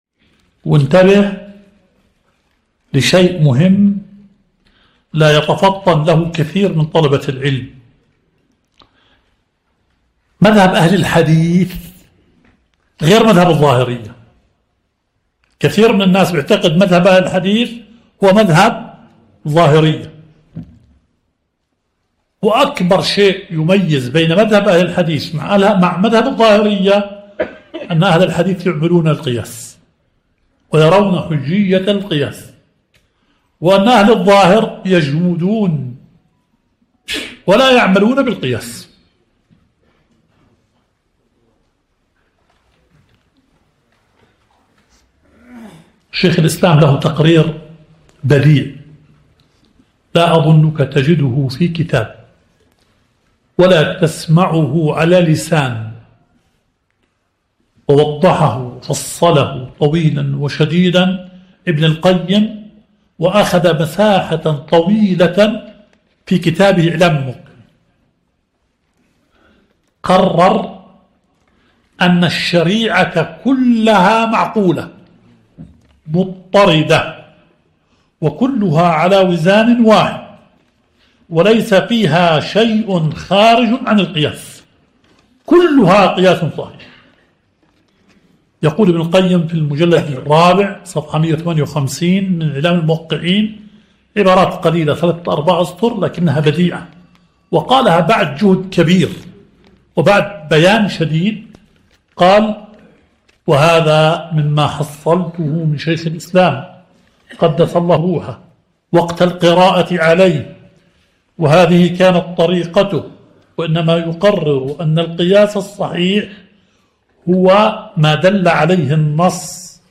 البث المباشر – درس شرح صحيح مسلم – فضيلة الشيخ مشهور بن حسن آل سلمان.